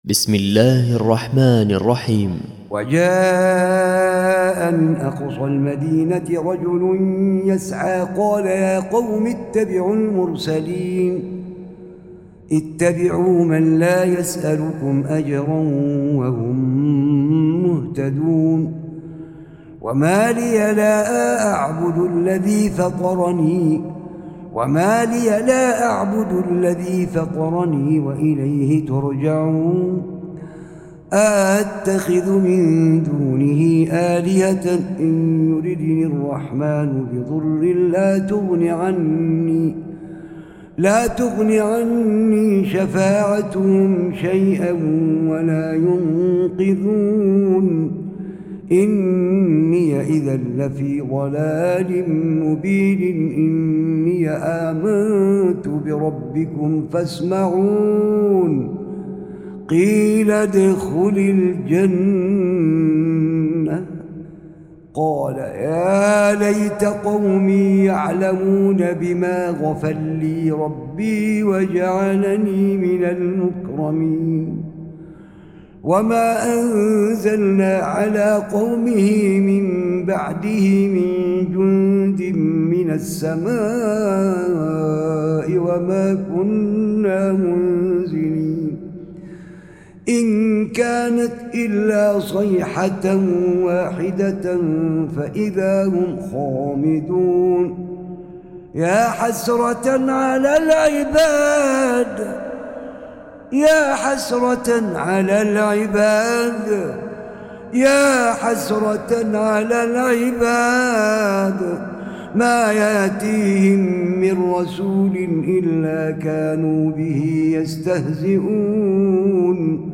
من محراب مسجد علي بن جبر بمنطقة الحد
:: من صلاة التراويح - ماتيسّر من سورة يس ::
تلاوة رائعة هادئة وخاشعة وقد صورها ( ياحسرة على العباد ) جميلة جداااااااا